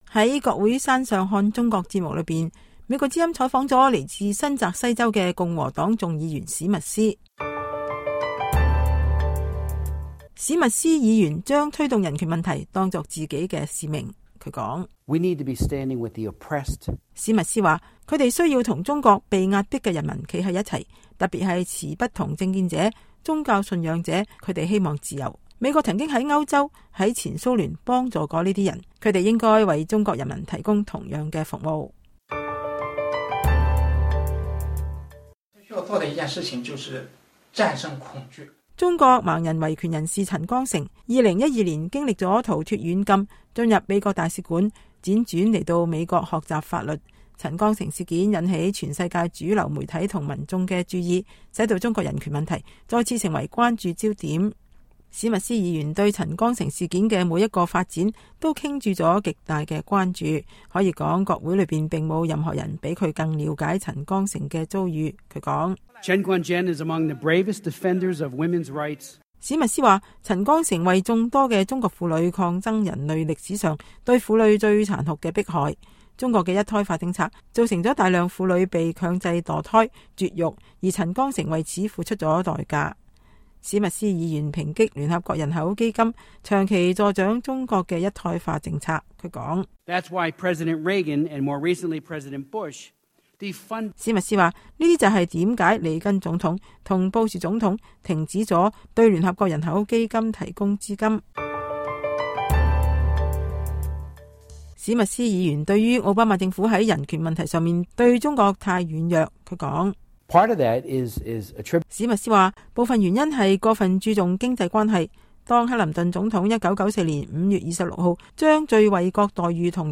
在今天的[國會山上看中國節目]中，我們採訪了來自新澤西州的共和黨眾議員史密斯。